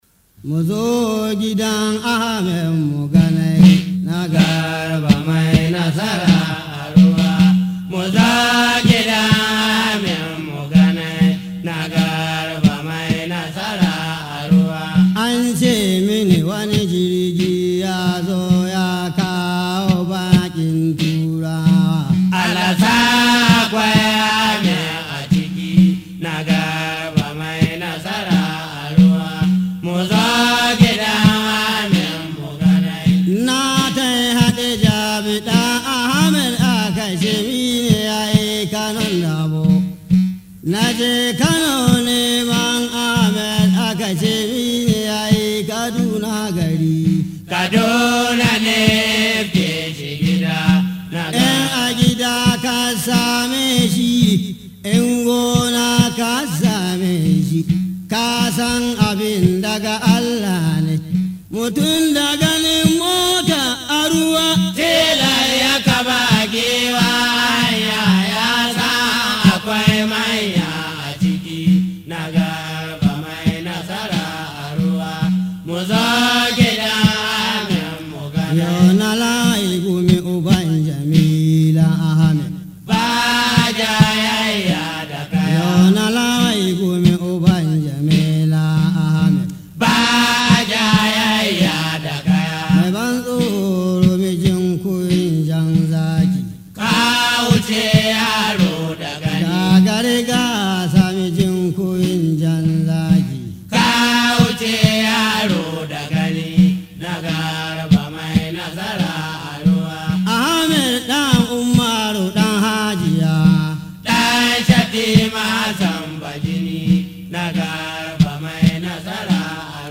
Wakokin Gargajiya